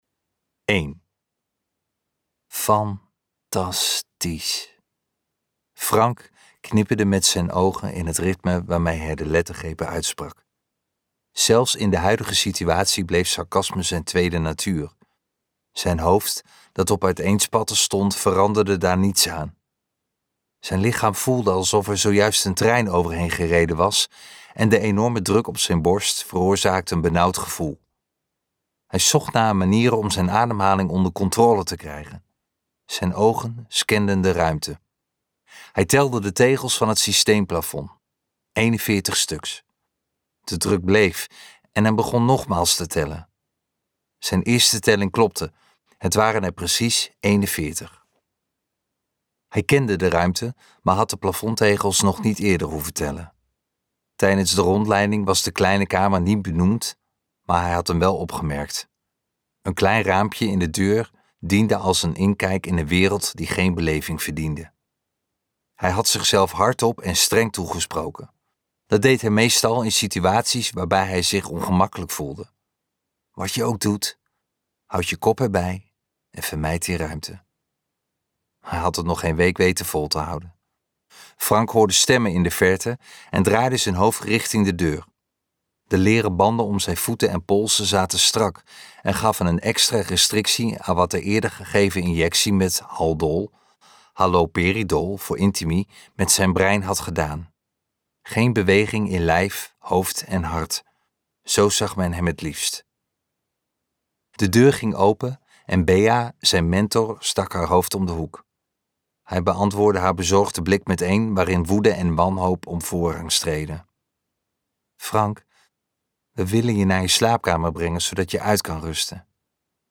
Ambo|Anthos uitgevers - Als de dood zucht luisterboek